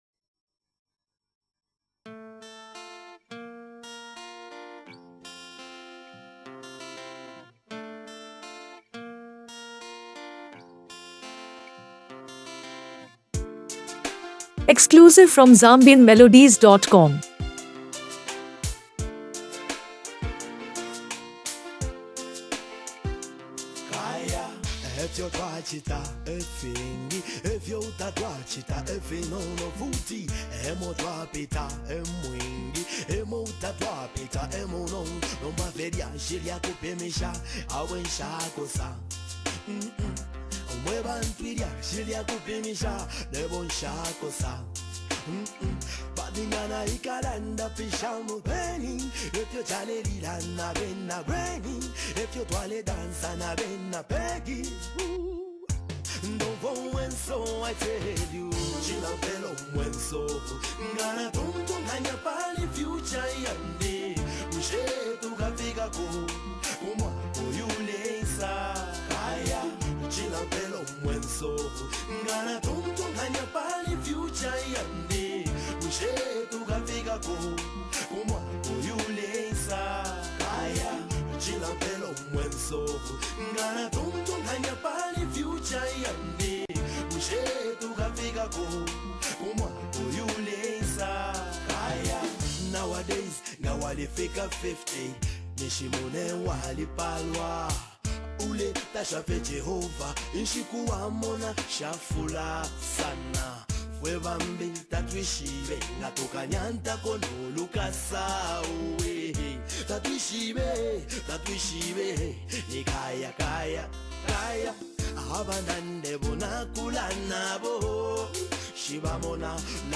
Known for blending powerful messages with soulful melodies